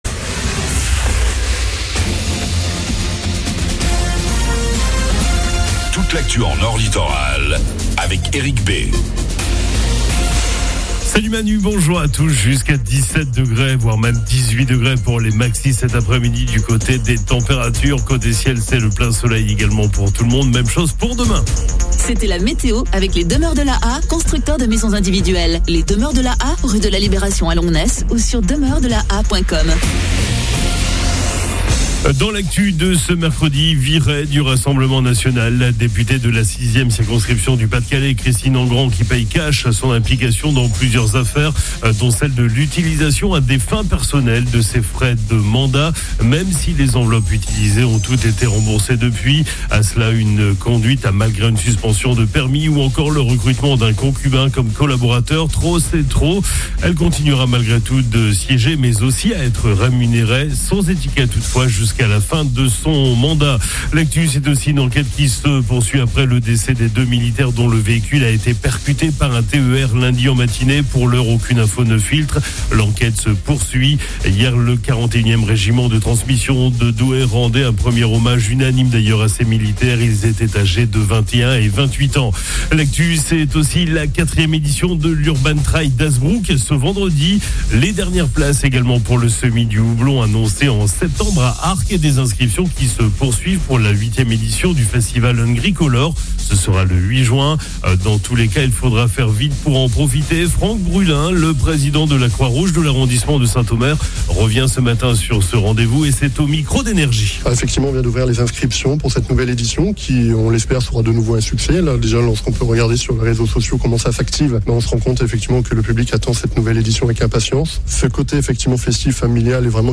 FLASH 19 MARS 25